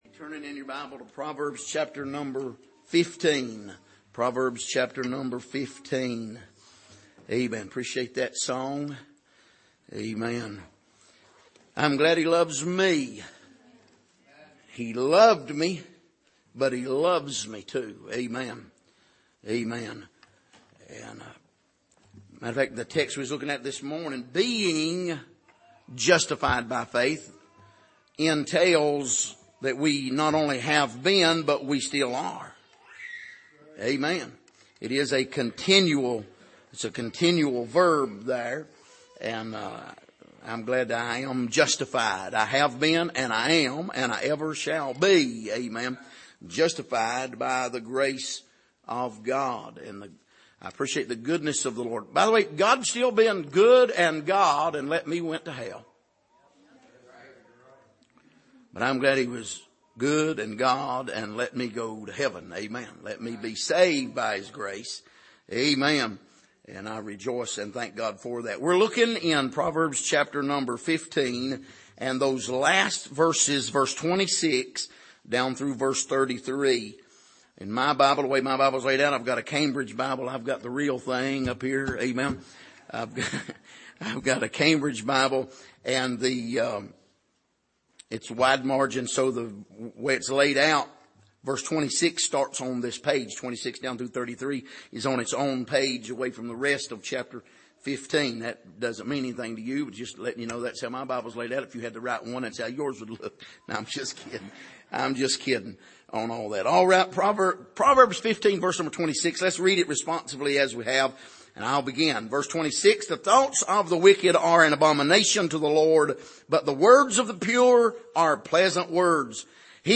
Passage: Proverbs 15:26-33 Service: Sunday Evening